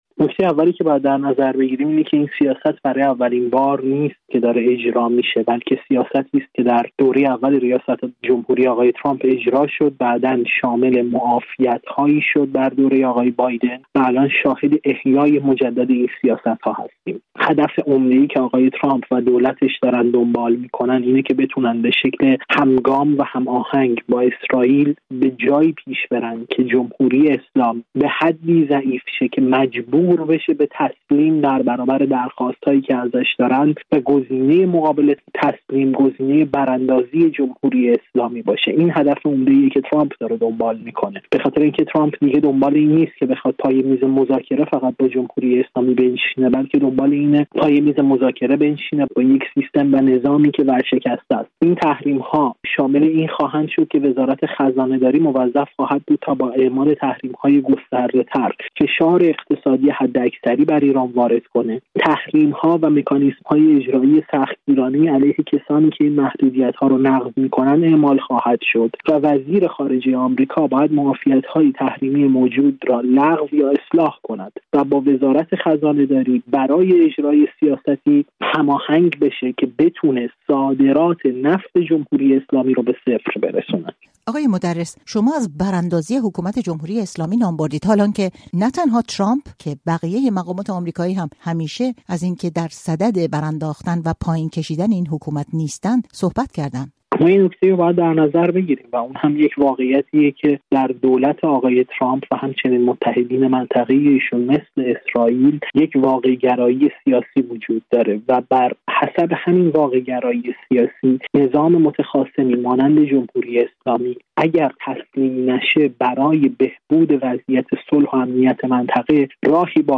کارشناس روابط بین‌الملل و تحلیلگر مطالعات امنیتی
در گفت‌وگو با رادیو فردا